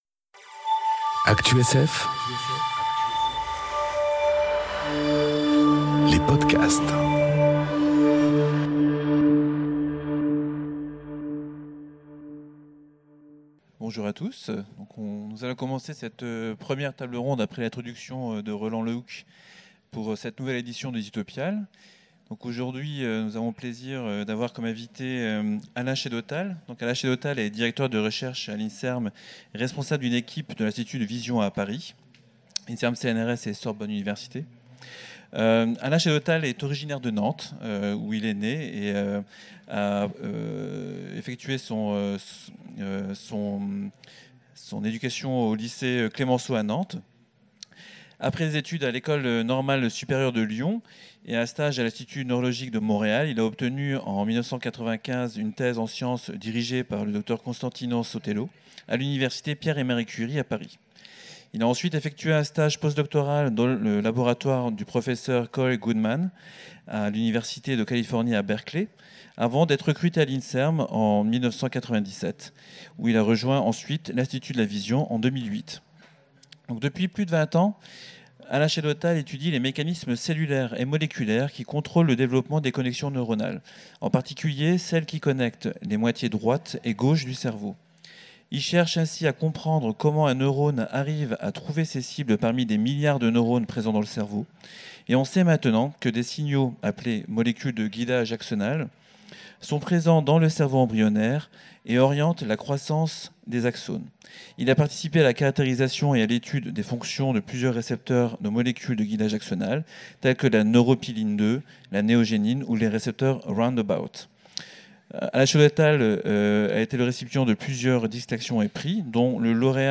Conférence Cartographie de l’embryon aux Utopiales 2018